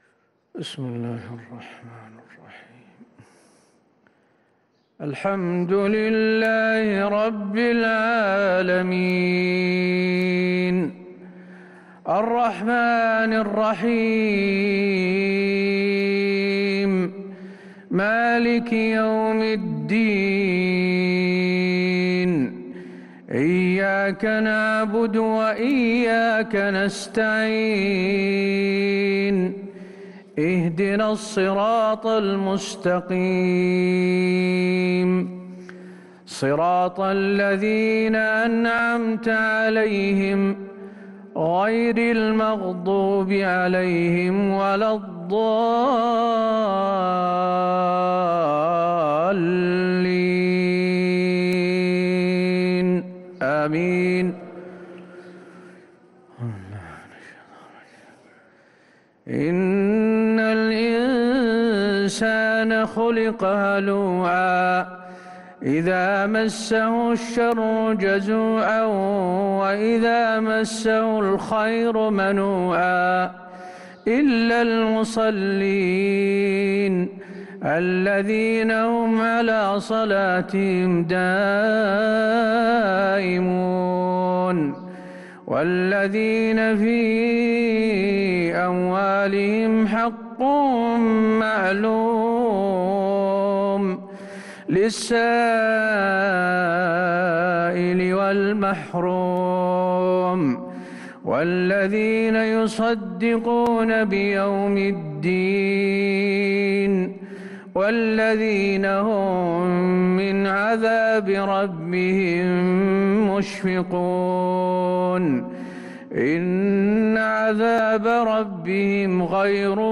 صلاة العشاء للقارئ حسين آل الشيخ 13 صفر 1443 هـ
تِلَاوَات الْحَرَمَيْن .